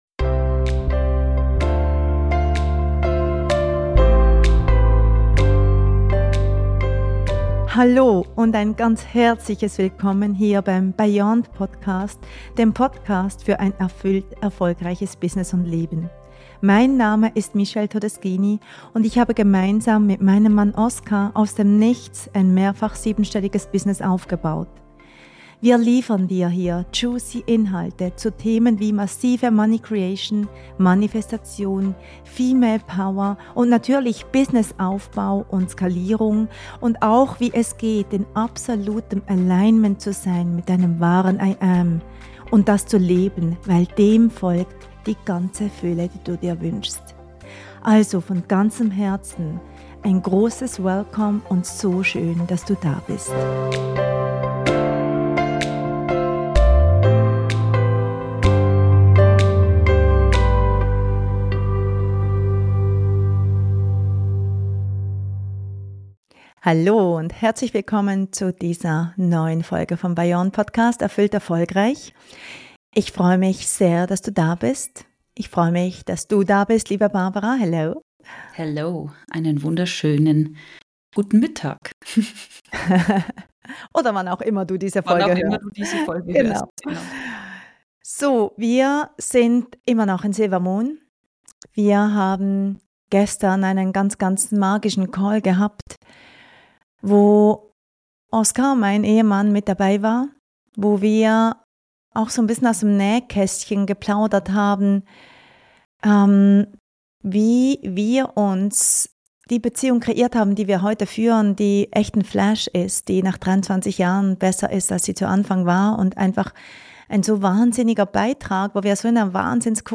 In diesem ehrlichen und tiefgehenden Gespräch